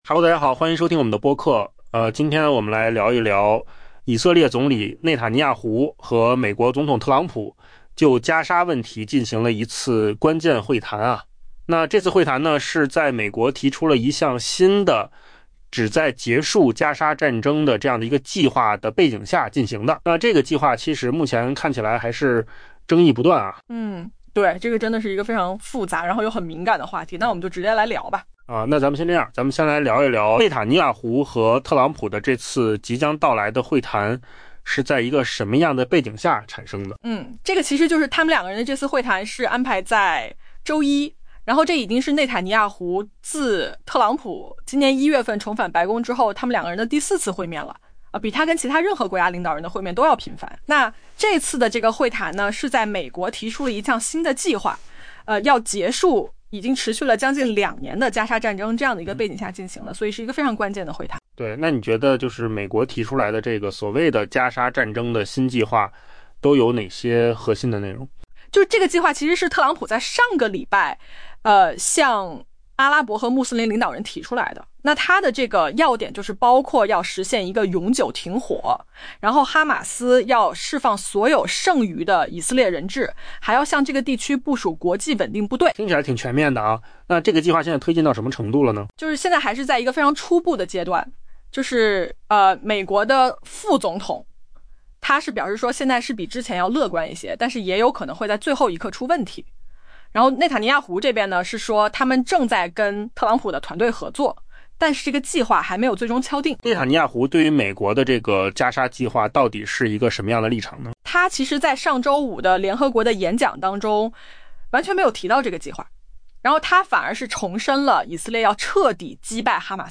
【文章来源：金十数据】AI播客：换个方
AI 播客：换个方式听新闻 下载 mp3 音频由扣子空间生成 以色列总理内塔尼亚胡定于周一在华盛顿与美国总统特朗普就加沙问题举行关键会谈，目前美国正在推动一项旨在结束已爆发近两年的加沙战争的新计划。